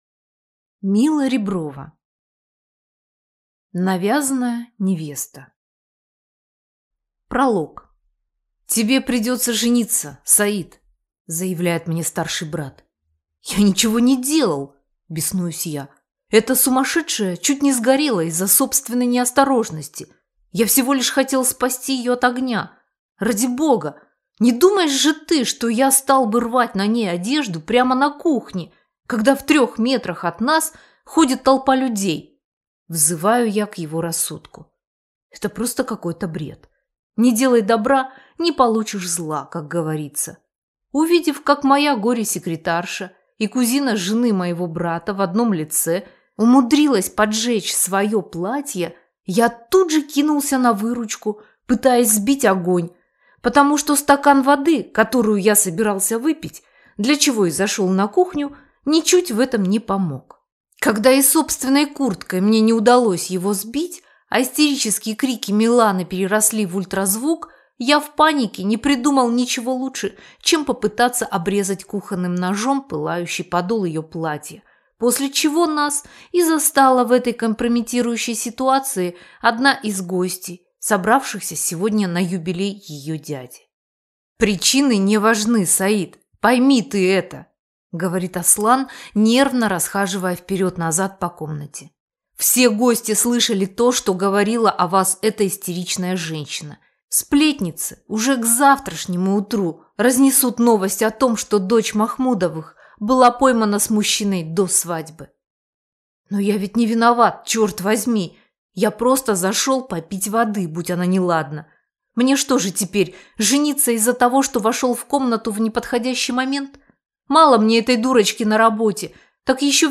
Аудиокнига Навязанная невеста | Библиотека аудиокниг